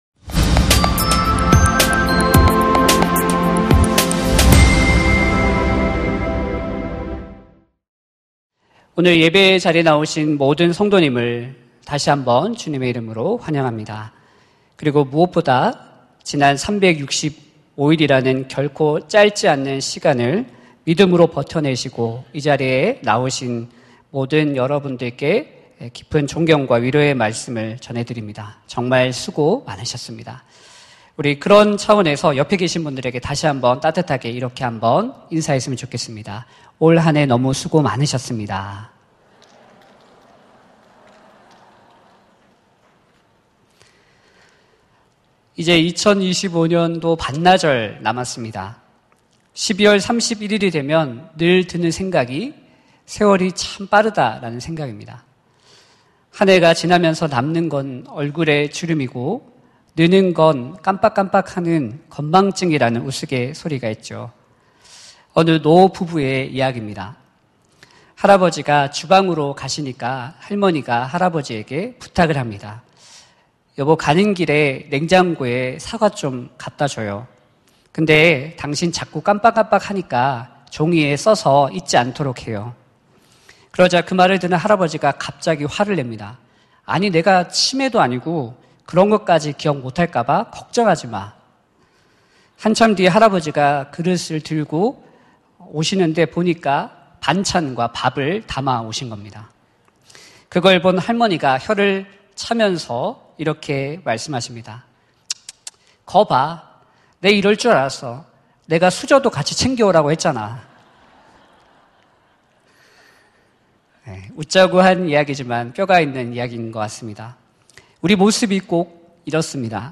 설교 : 수요향수예배